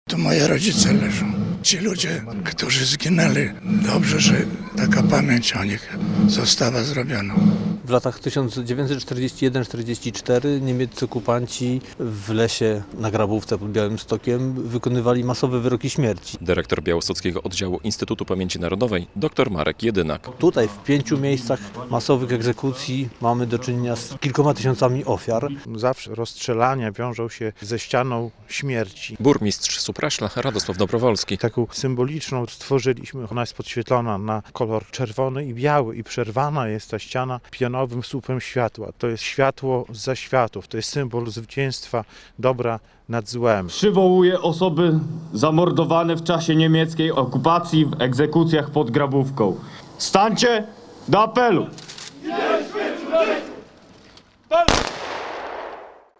W Grabówce odsłonięto odrestaurowane miejsce upamiętniające ofiary zbrodni hitlerowskiej - relacja